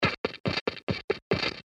描述：节奏流畅，打击乐，中性环境。
Tag: 120 bpm Hip Hop Loops Drum Loops 435.24 KB wav Key : Unknown